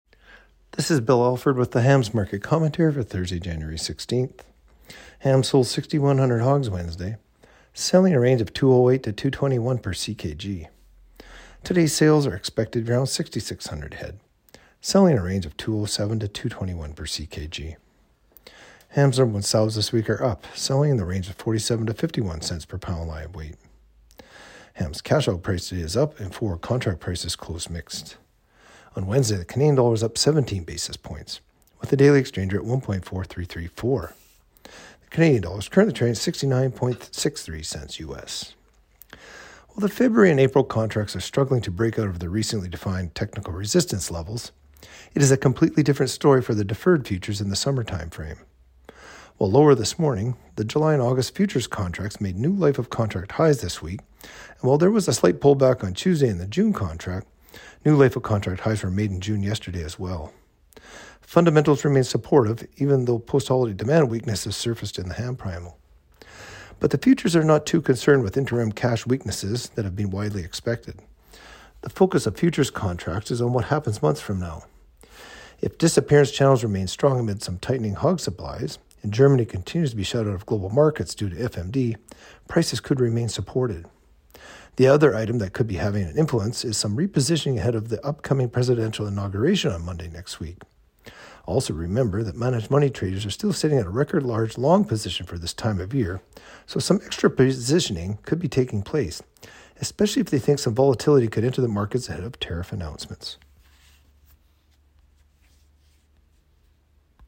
Market-Commentary-Jan.-16-25.mp3